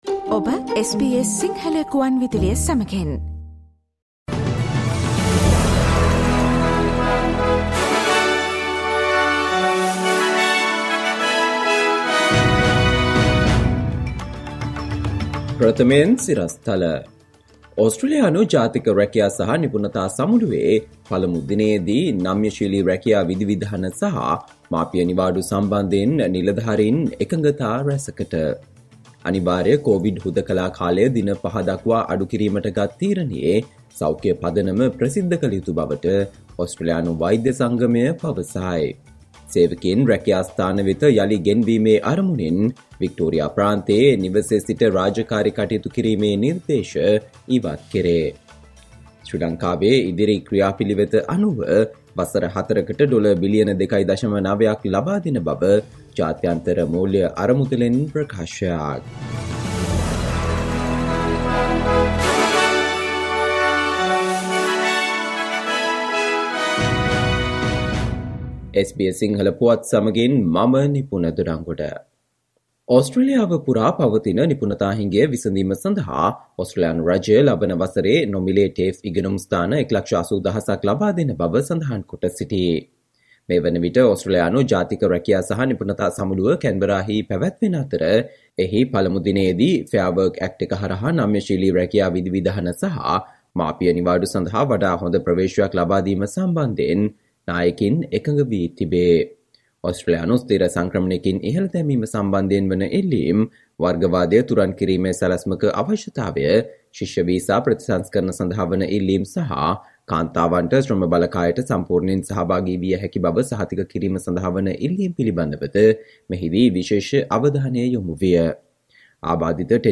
Listen to the SBS Sinhala Radio news bulletin on Friday 02 September 2022